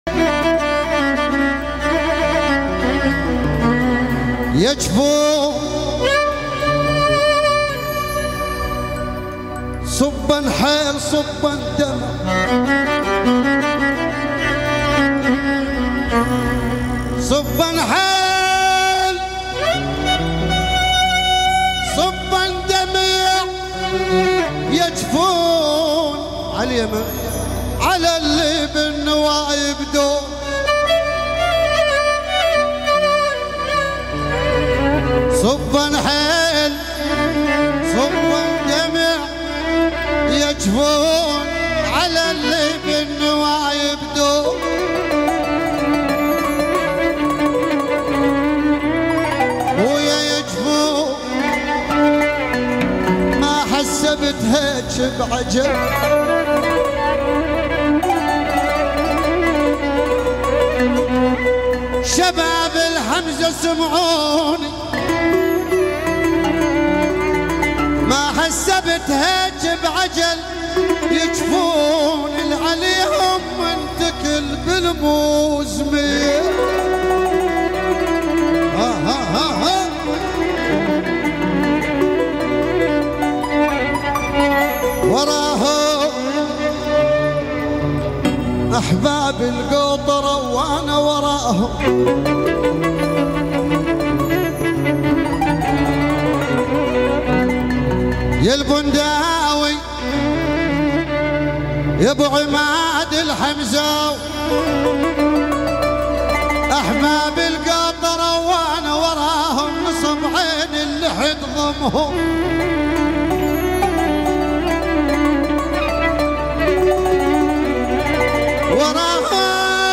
آهنگ عربی